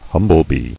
[hum·ble·bee]